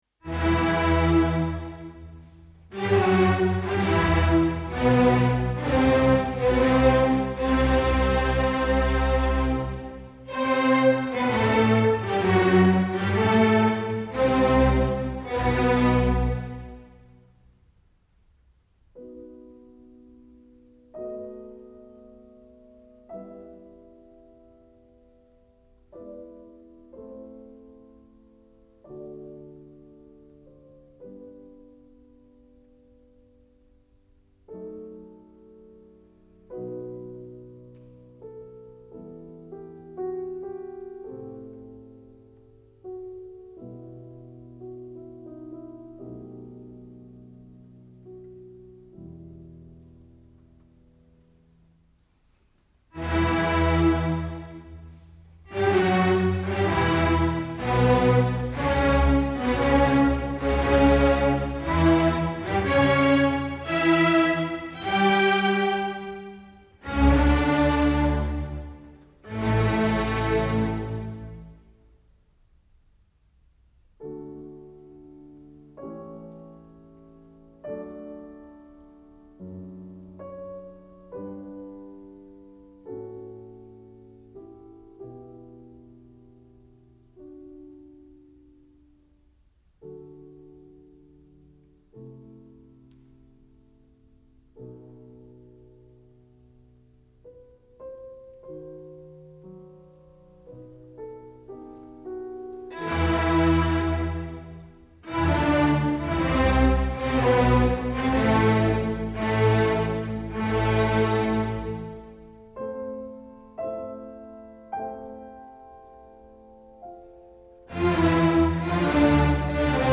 L v Beethoven Piano Concerto No. 4 G Major – II. Andante con moto, D Barenboim